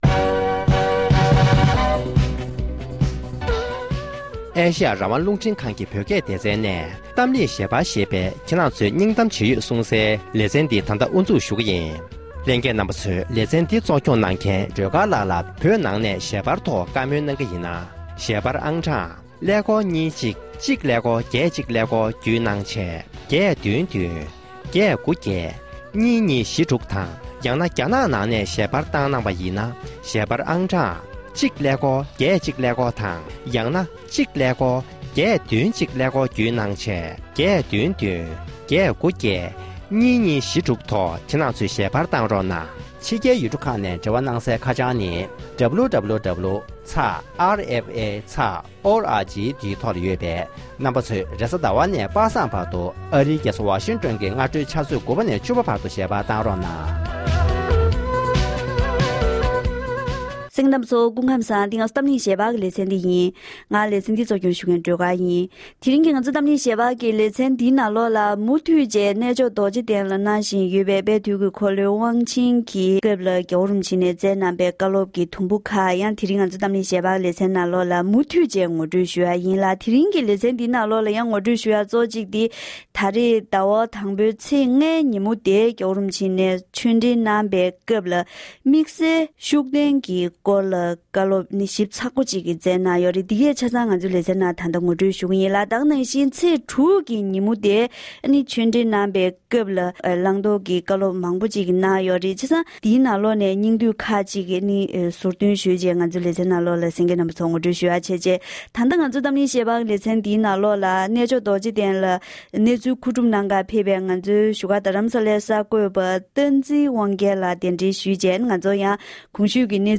༸གོང་ས་མཆོག་ནས་ཆོས་འབྲེལ་སྐབས་དམིགས་བསལ་དོལ་རྒྱལ་སྐོར་གནང་བའི་བཀའ་སློབ།